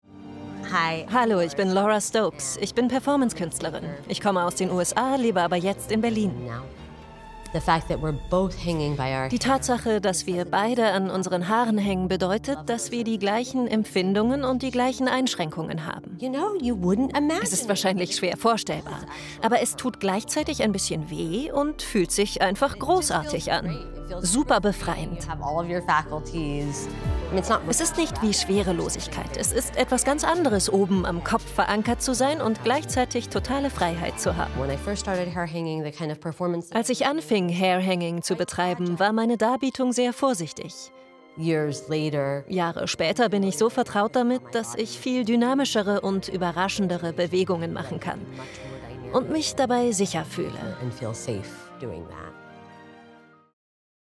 sehr variabel, hell, fein, zart